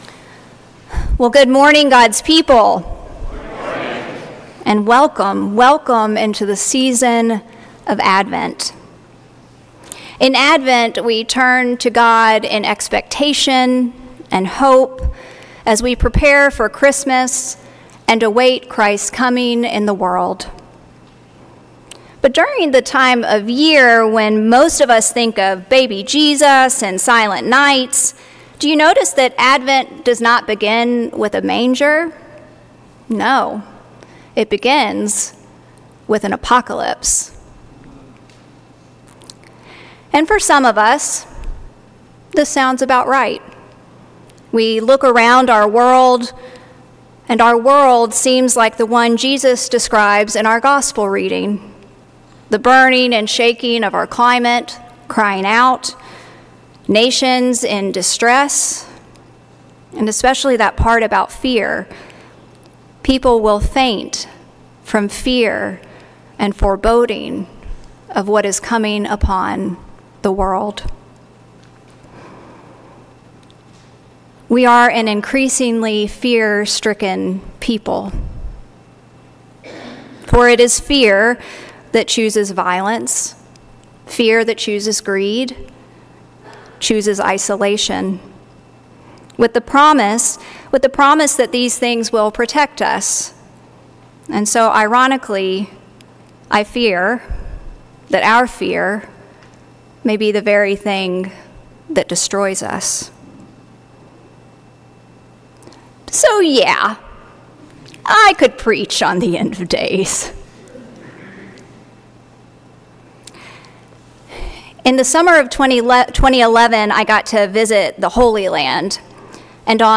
Sermons - St. Peter's Church